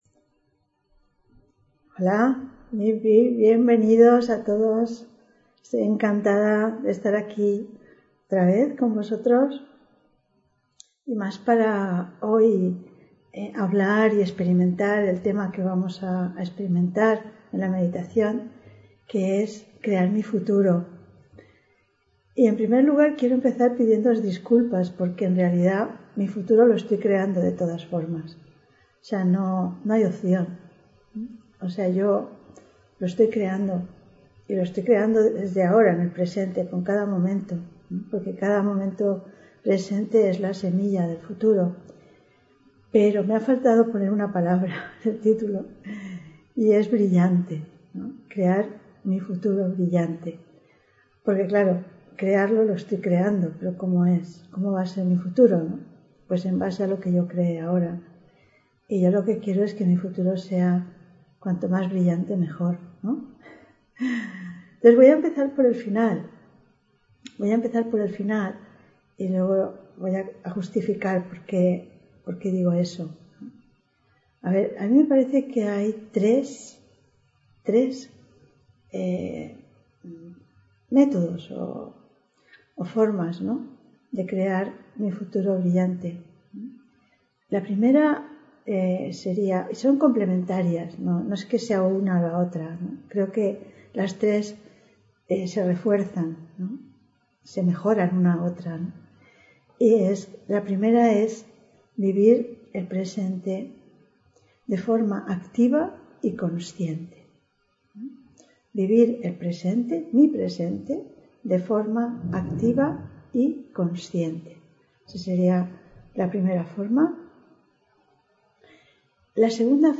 Meditación y conferencia: Yo creo mi futuro (29 Enero 2023)